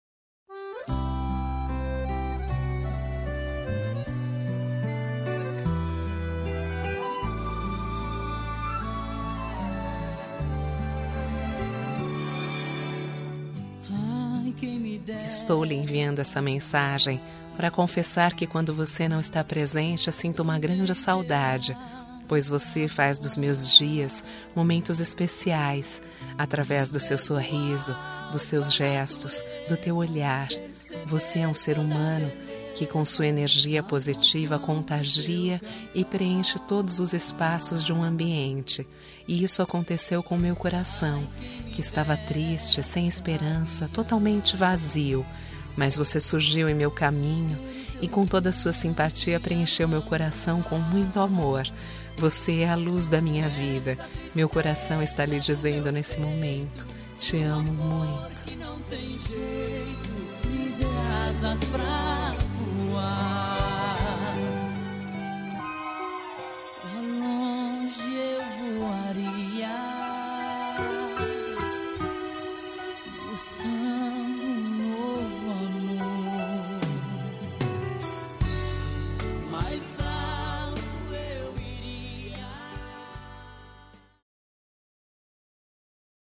Telemensagem de Conquista – Voz Feminina – Cód: 140108